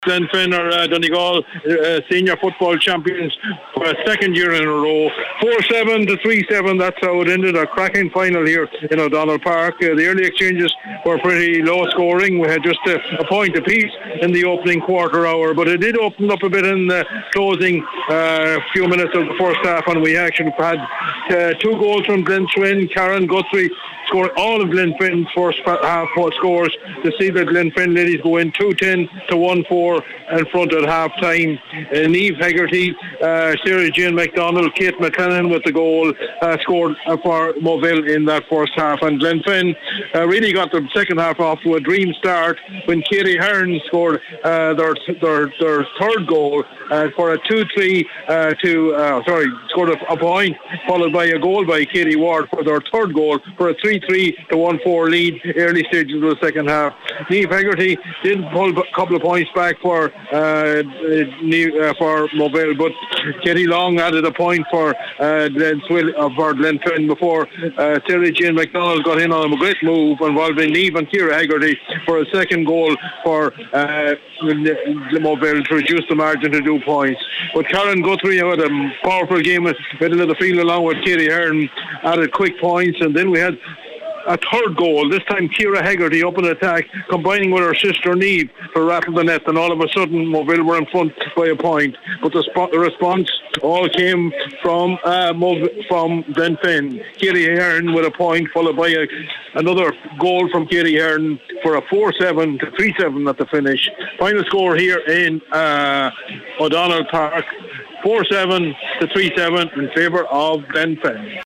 full-time report